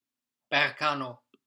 How to pronounce Berkano